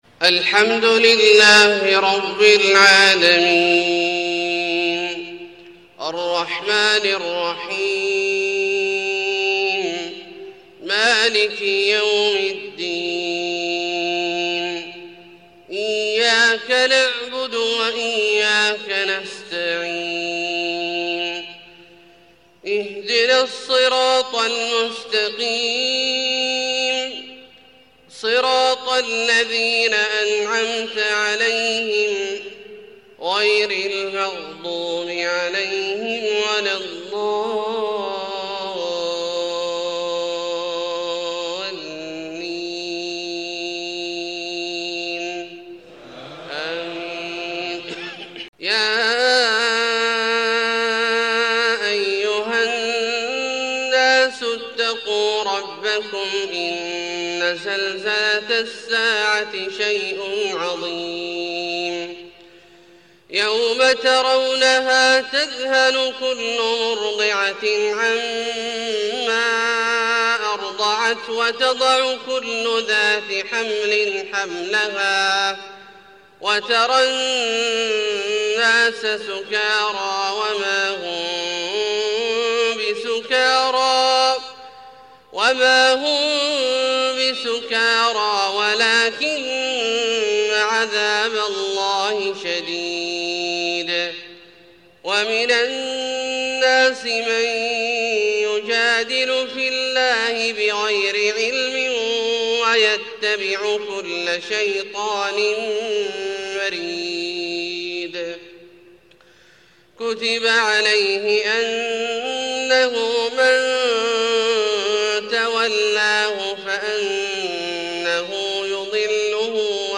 فجر 1-5-1432هـ من سورة الحج {1-13} > ١٤٣٢ هـ > الفروض - تلاوات عبدالله الجهني